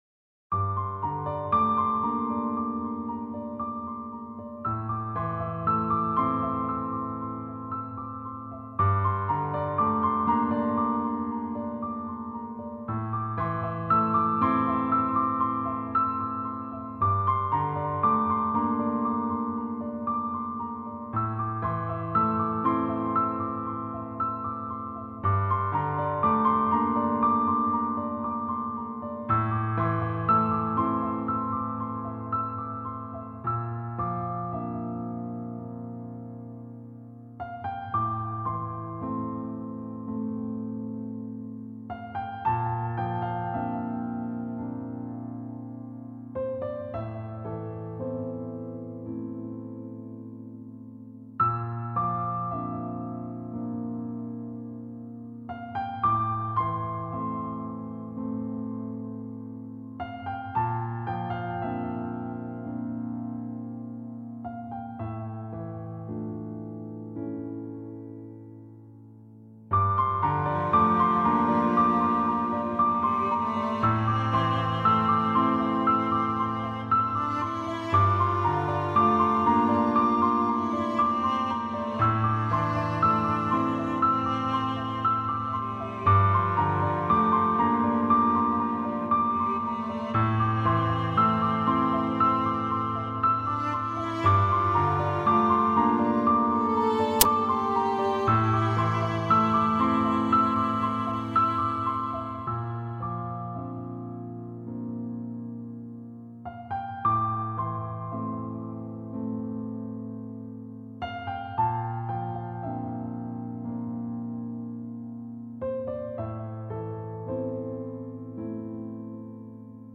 低沉的打击乐仿佛敲响了永乐朝的大钟
梦醒时分，大幕落下，看尽世事，洗尽铅华，带着丝丝感伤、点点无奈，乐声渐渐隐去。